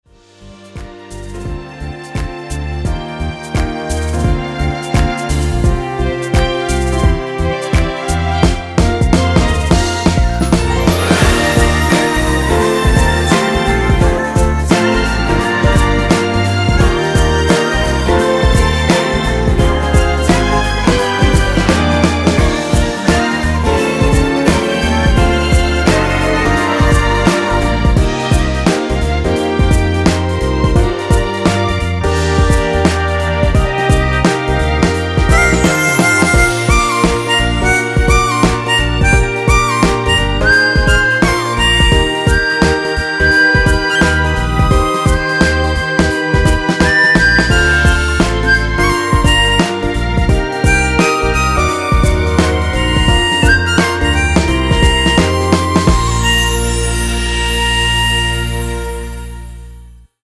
엔딩이 페이드 아웃이라서 노래하기 편하게 엔딩을 만들어 놓았으니 미리듣기 확인하여주세요!
원키에서(+2)올린 코러스 포함된 MR입니다.
Bb
앞부분30초, 뒷부분30초씩 편집해서 올려 드리고 있습니다.